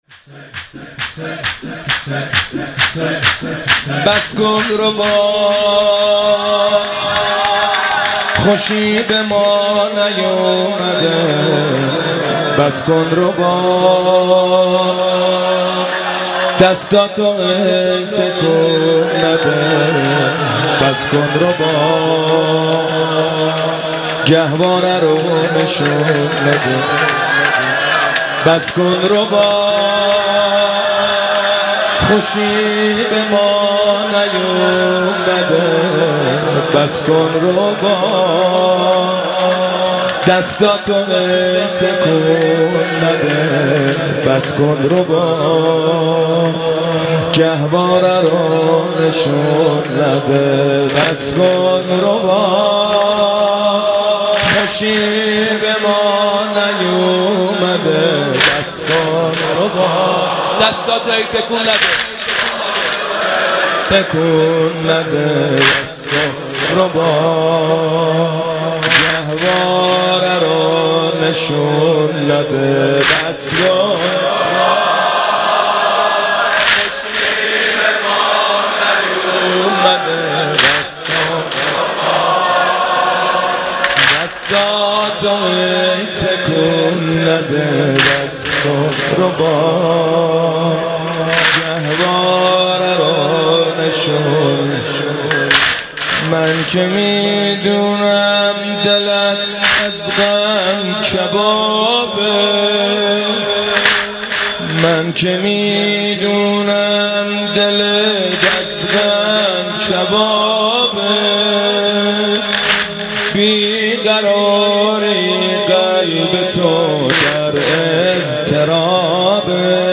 مداحی شهادت امام سجاد